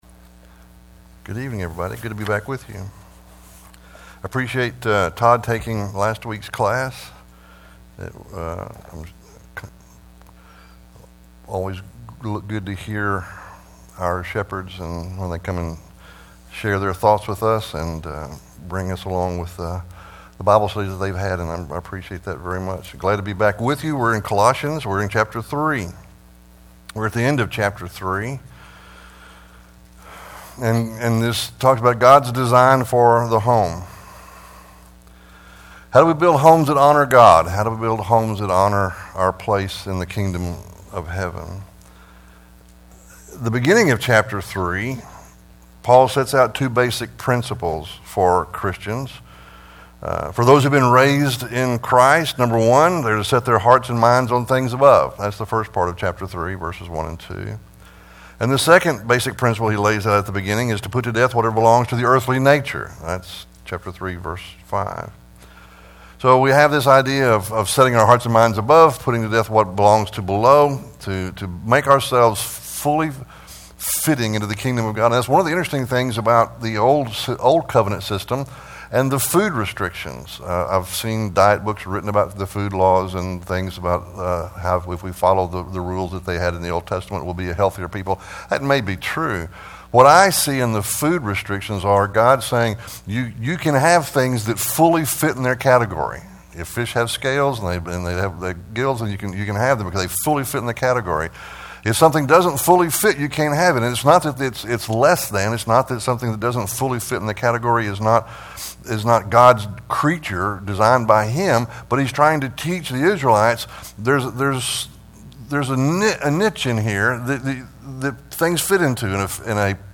2025 God Give Us Christian Homes Preacher